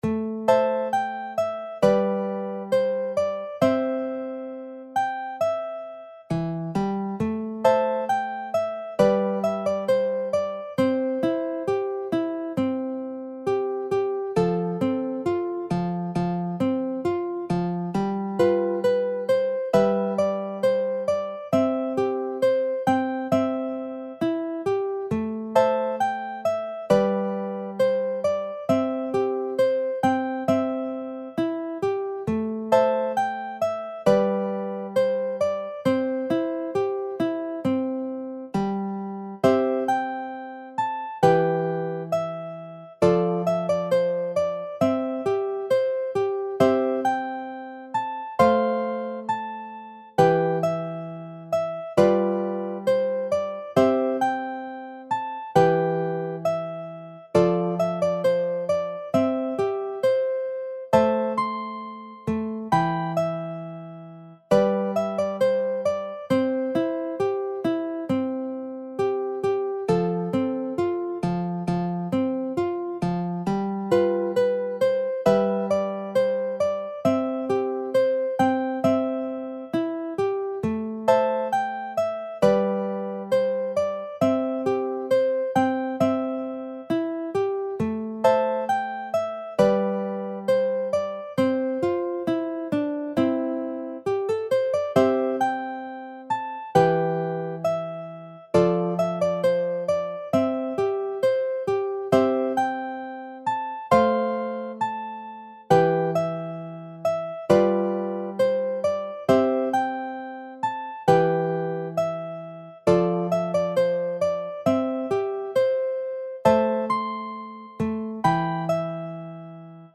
J-POP / フォーク・ニューミュージック
楽譜の音源（デモ演奏）は下記URLよりご確認いただけます。
（この音源はコンピューターによる演奏ですが、実際に人が演奏することで、さらに表現豊かで魅力的なサウンドになります！）